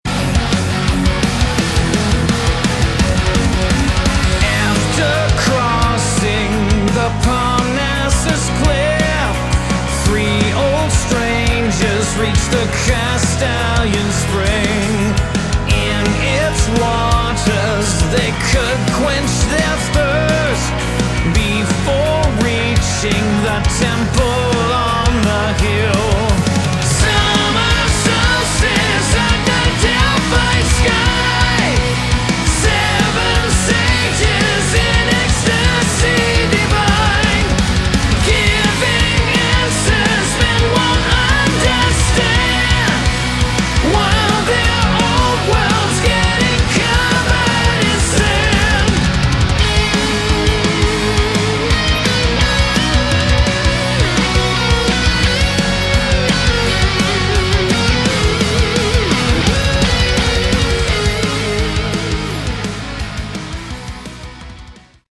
Category: Melodic Metal
vocals
guitars
keyboards & piano
bass
drums